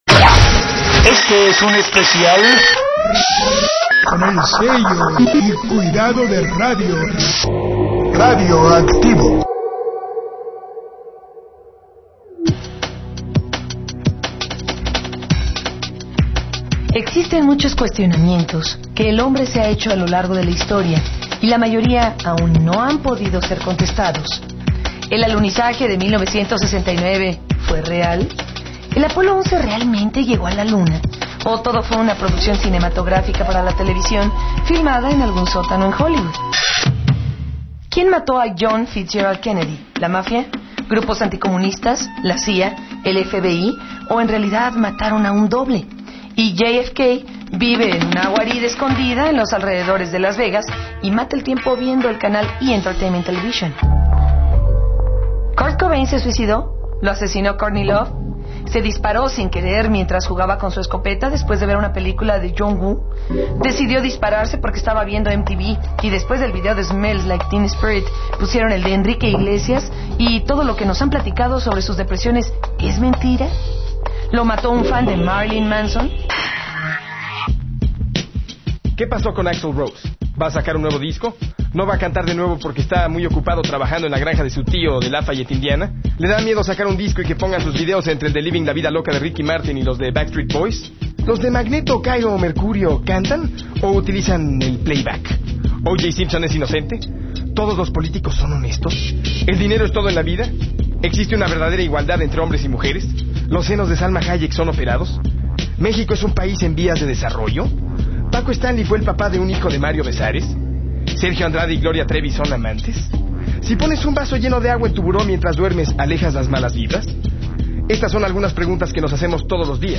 Entrevista a Alejandro Jodorowsky
La desaparecida estación de radio Radioactivo 98.5 FM de la ciudad de Mexico realizó la siguiente entrevista al Mtro. Alejandro Jodorowsky.